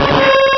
Cri de Migalos dans Pokémon Rubis et Saphir.
Cri_0168_RS.ogg